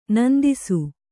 ♪ nandisu